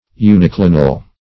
Uniclinal \U`ni*cli"nal\